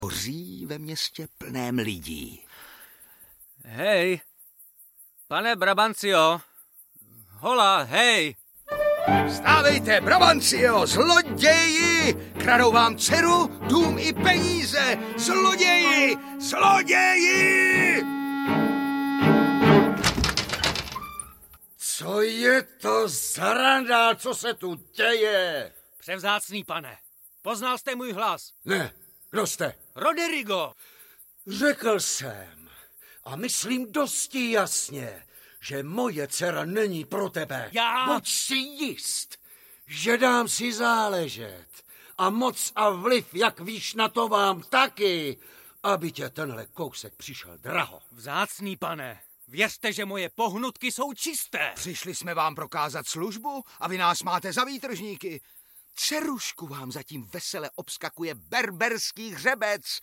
Audiobook
Read: David Švehlík